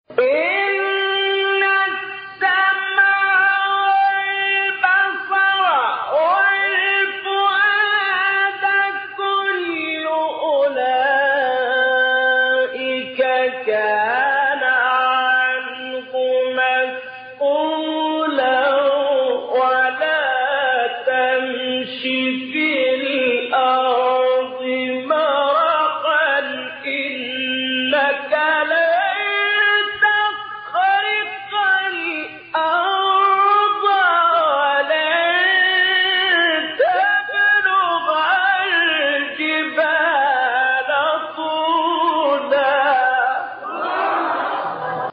گروه شبکه اجتماعی: مقاطعی صوتی با صوت محمد اللیثی را که در مقام‌های مختلف اجرا شده است، می‌شنوید.
به گزارش خبرگزاری بین المللی قرآن(ایکنا) پنج فراز صوتی از سوره مبارکه اسراء با صوت محمد اللیثی، قاری برجسته مصری در کانال تلگرامی قاریان مصری منتشر شده است.
مقام سه گاه